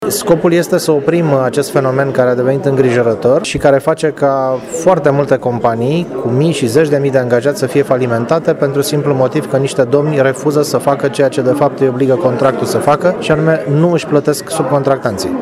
Secretarul de stat Cătălin Beciu a declarat, la Tg.Mureş, că societăţile vor trebui să depună procesul verbal încheiat cu subcontractorii lor de la lucrările anterioare, din care să rezulte că obligațiile financiare dintre părți au fost stinse: